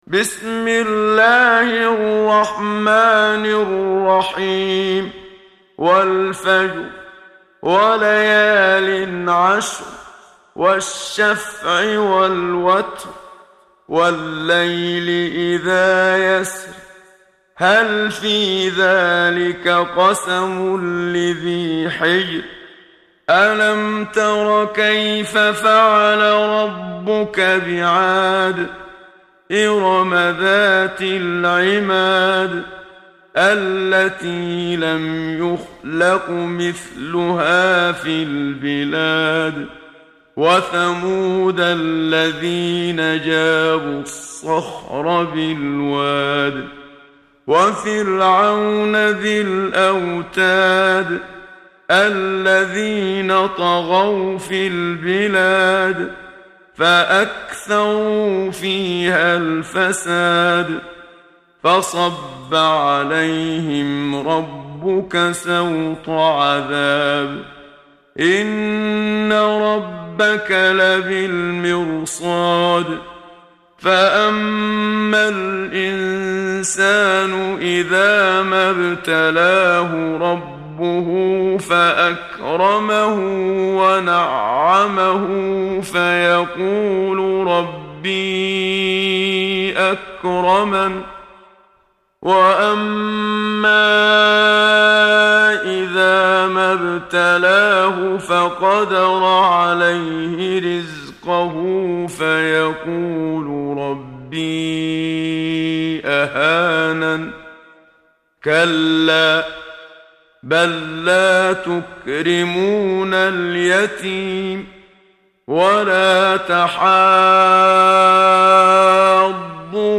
محمد صديق المنشاوي – ترتيل